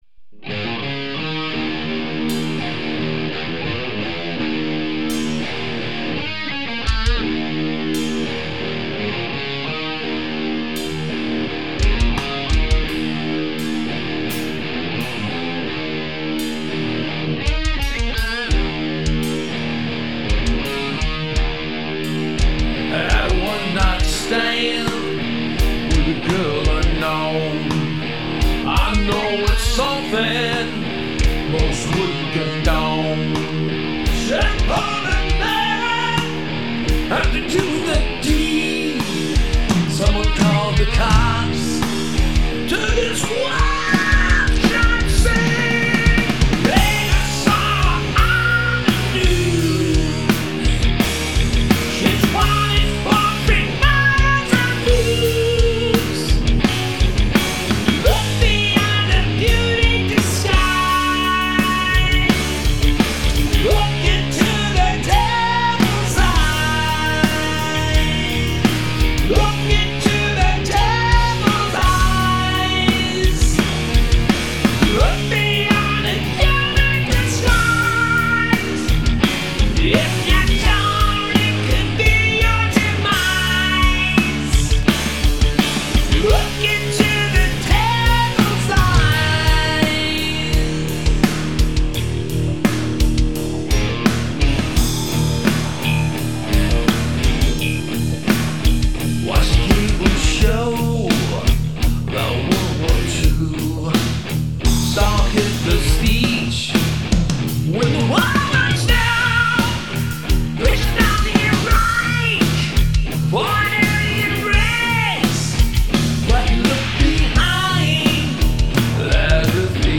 Didn't spend too much time on this (mixed in headphones), but I thought I would put this up so you guys could spots any problems early on. thx